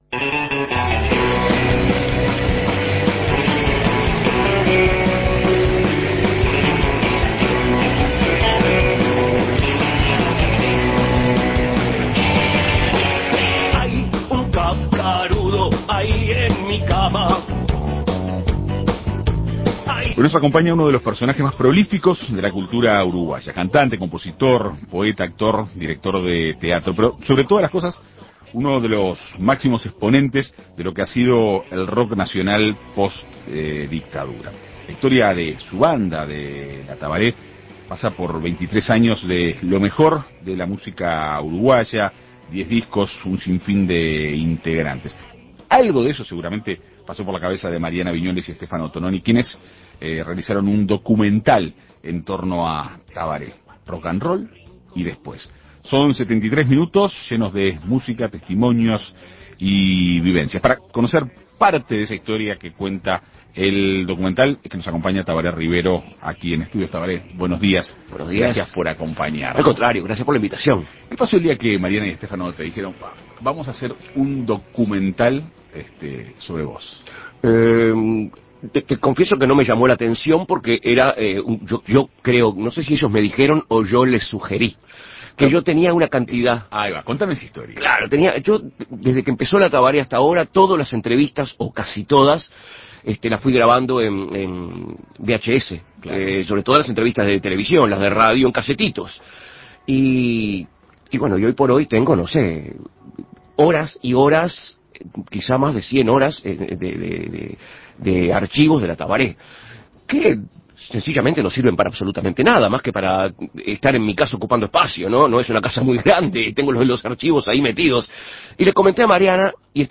Este viernes En Perspectiva Segunda Mañana dialogó con Tabaré Rivero, entre otras cosas, sobre el documental que le propusieron realizar acerca de la banda que lidera: La Tabaré.